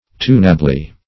tunably - definition of tunably - synonyms, pronunciation, spelling from Free Dictionary
-- Tun"a*ble*ness , n. -- Tun"a*bly , adv.